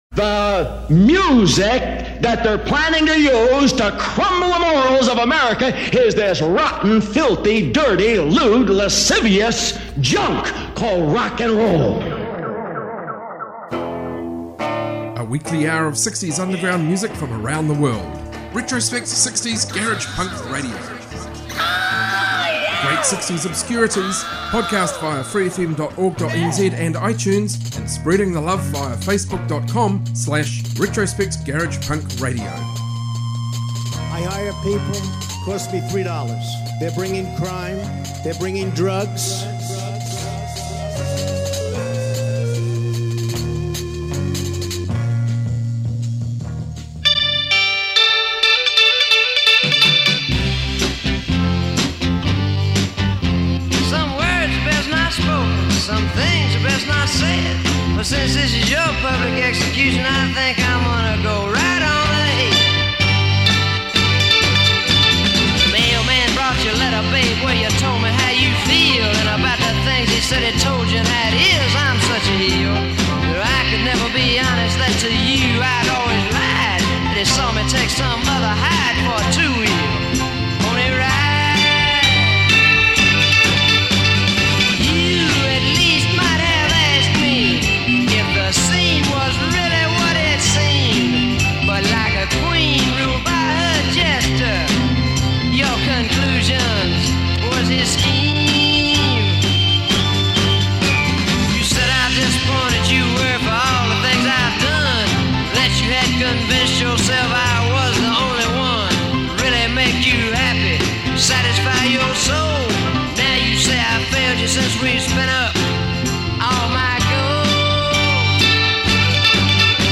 60s garage punk, freakbeat and so on.